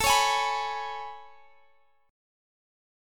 Listen to AM9 strummed